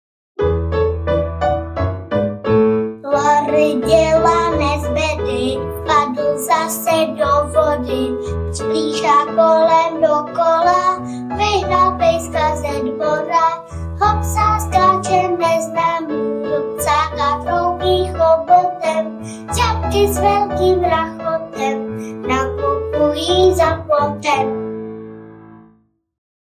Loriho peripetie audiokniha
Je o nezbedném slůněti Lorim a jeho šibalských kouscích. Ke každé pohádce najdete písničku, kterou si můžete i zazpívat.
Ukázka z knihy